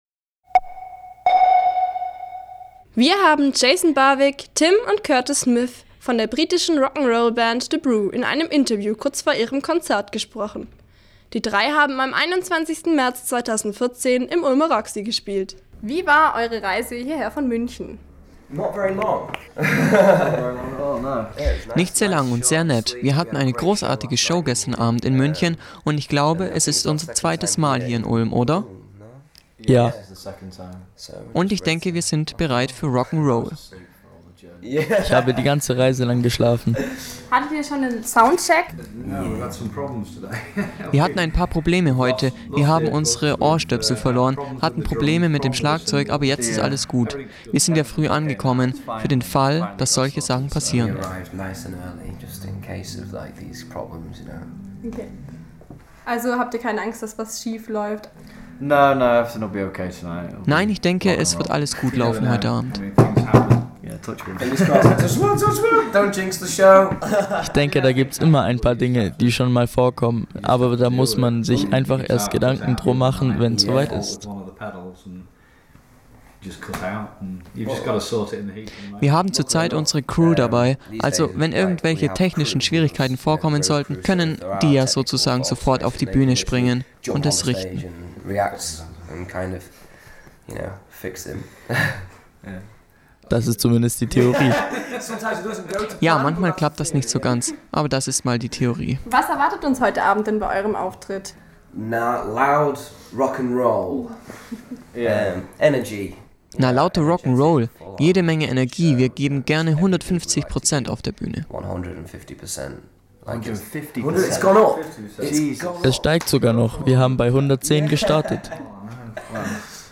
Ulmer Freiheit :: Interview The Brew :: 26.03.2014
interview_the_brew_syncro_0.mp3